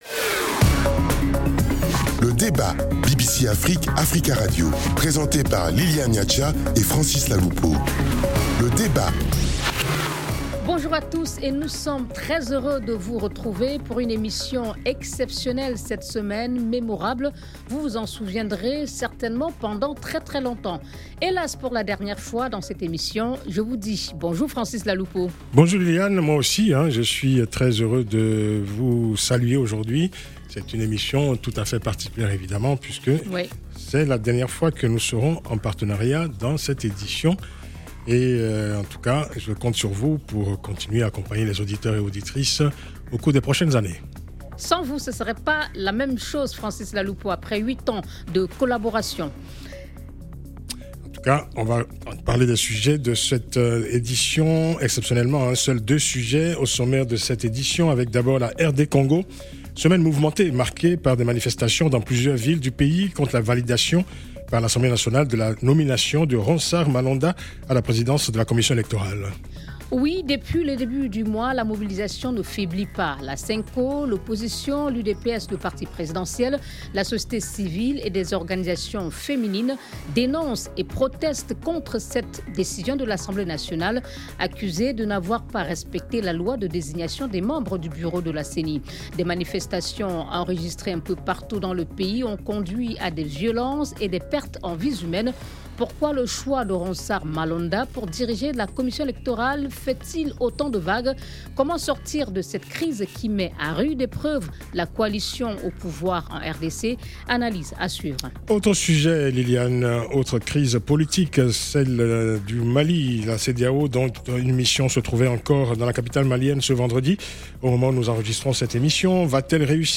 Invitée : Jeanine Mabunda , présidente Assemblée Nationale (RDC)